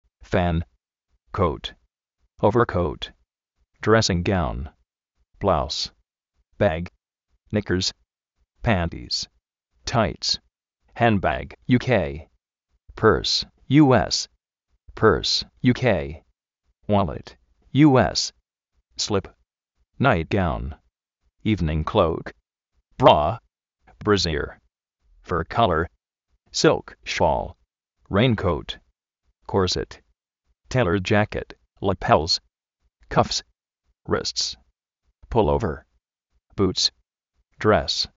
óver(kóut)
drésin-gáun
bláus
jándbag (UK)
uólit (US)
náit-góun